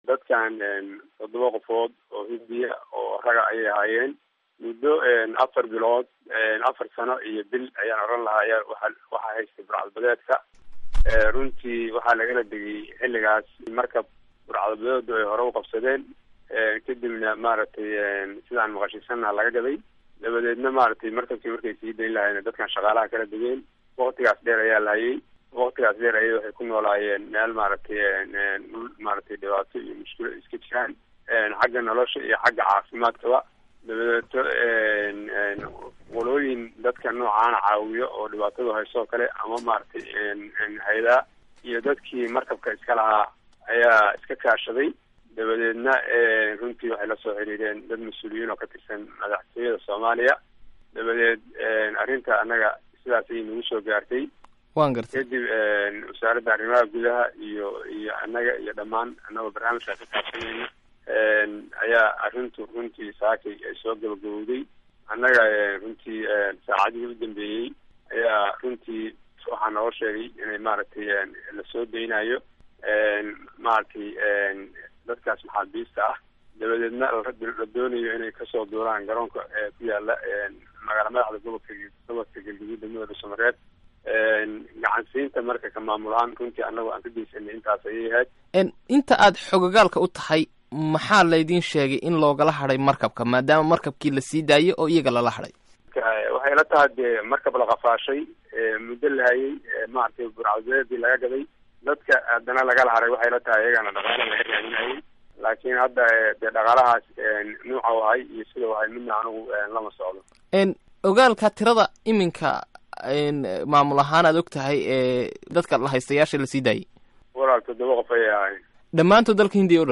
Waraysiga La haystayaal u dhashay India oo la sii daayey